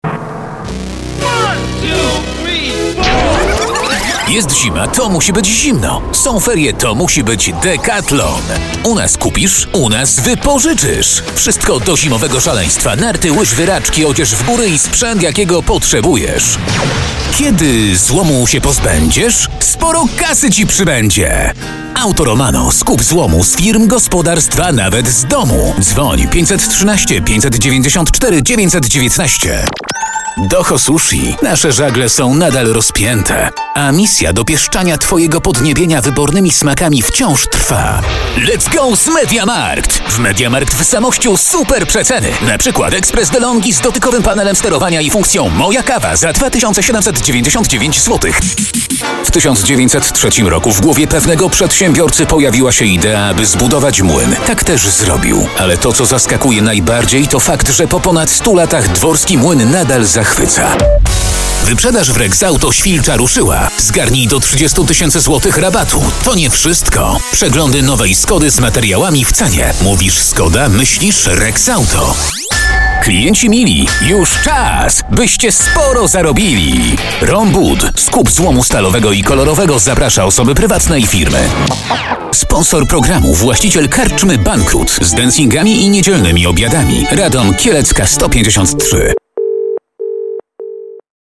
Mężczyzna 30-50 lat
Nagranie lektorskie